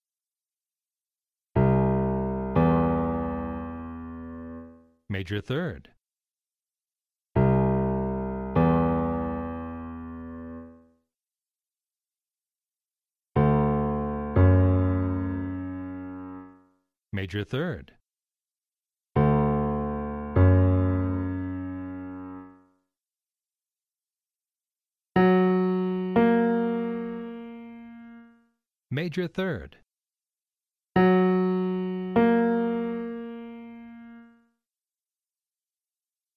Preview: Major Third (up)
Additionally, it’s important to pay attention to the spoken label for each interval, so you learn to associate the sound of the interval with its name.
Preview-2-Major-Third-up.mp3